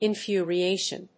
infuriation.mp3